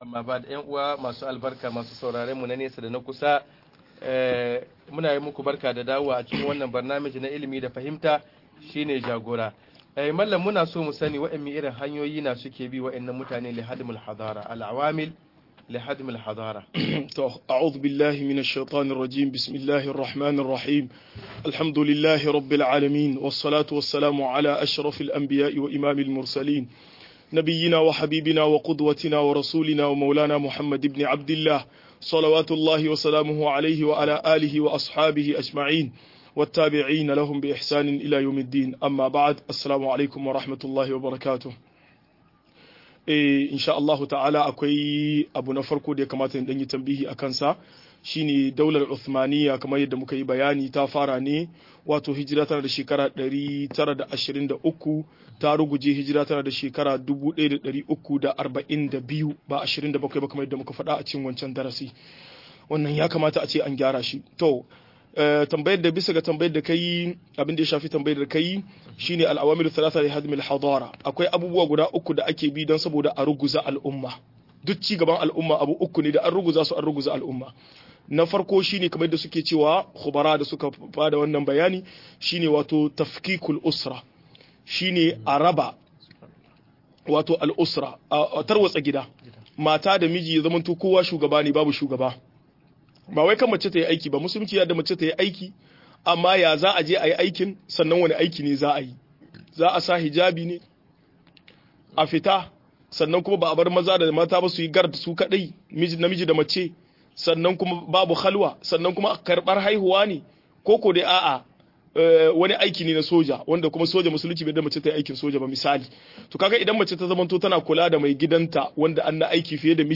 Halin musulamai a duniyar yau-02 - MUHADARA